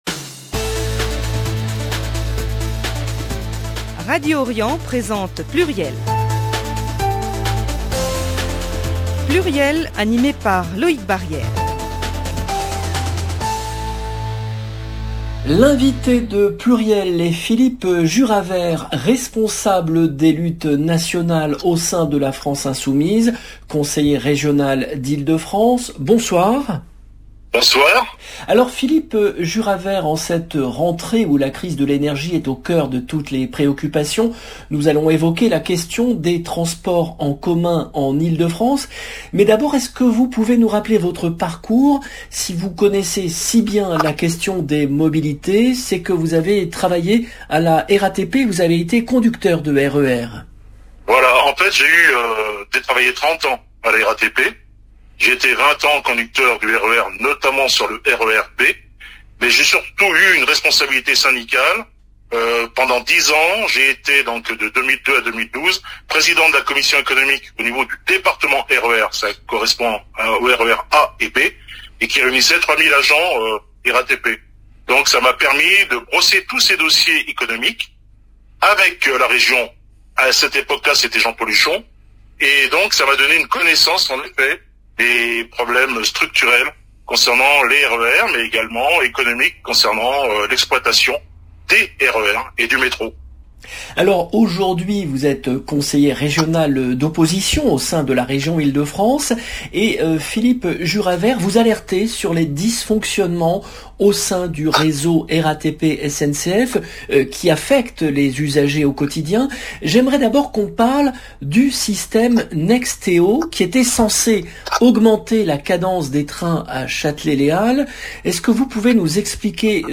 L’invité de PLURIEL est Philippe Juraver, responsable des luttes nationales au sein de la France Insoumise, conseiller régional d’Ile-de-France